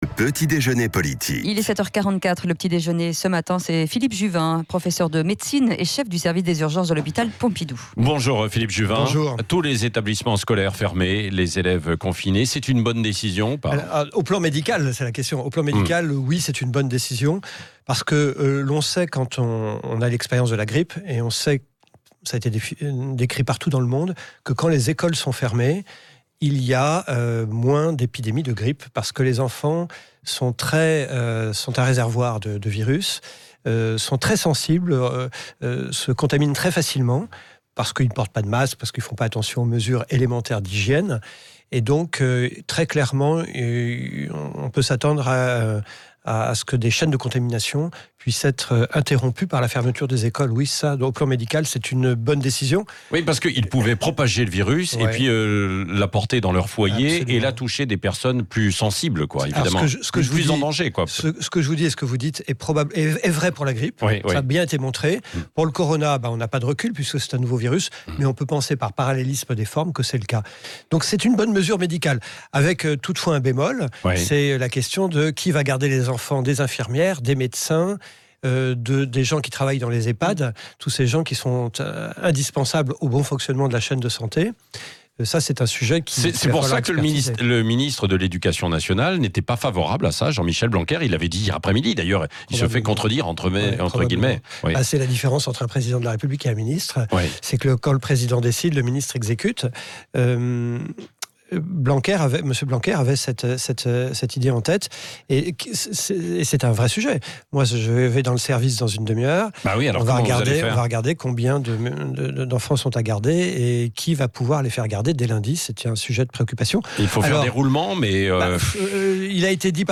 Philippe Juvin, professeur de médecine et Chef de service des urgences de l’hôpital Pompidou, est l’invité politique du Grand Matin à la Radio.